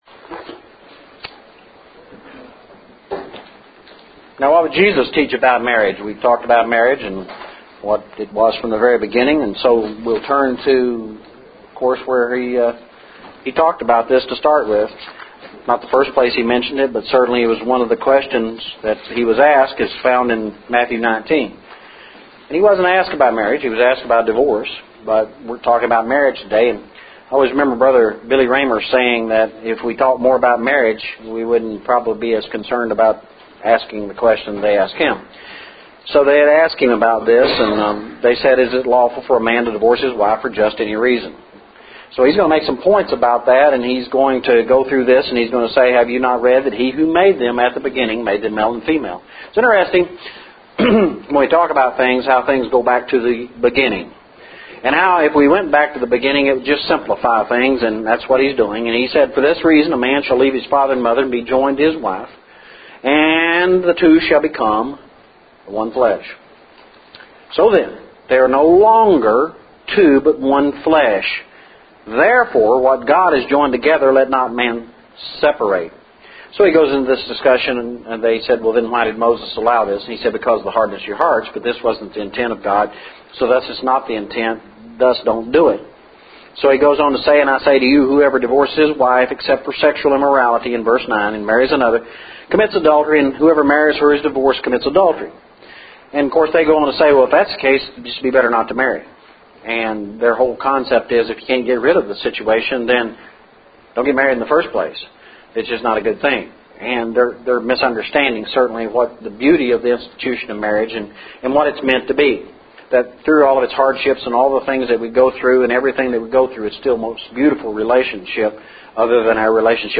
Marriage Lessons – 02/06/11